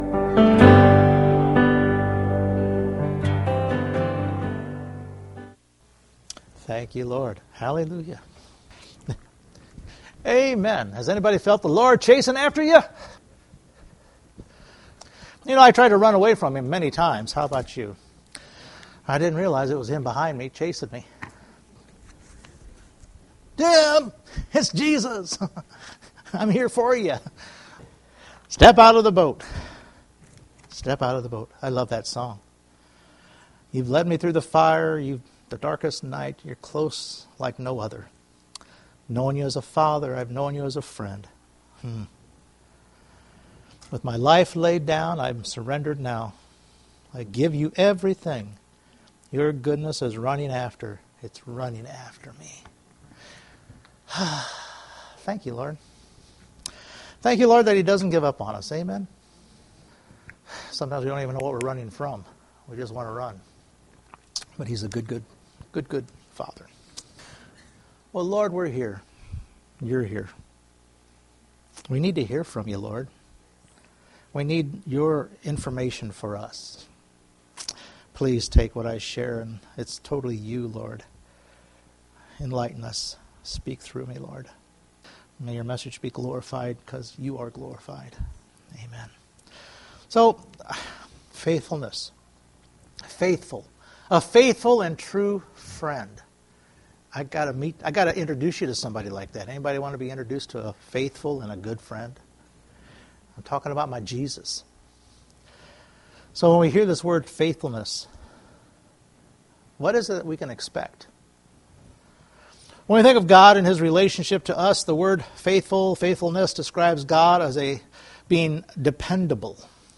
Several Shared Service Type: Sunday Morning We can be assured of the faithfulness and the truthfulness of our God.